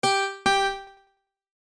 Yeah, there's NO WAY it's not a Mandolin.
I lowered the Cut on the Touhou insert, and upon playing it back, I could now safely say that the only difference here besides compression level was the low string being played louder than the high string like in WL4's. Attachments doge mandolio.mp3 doge mandolio.mp3 27.8 KB · Views: 388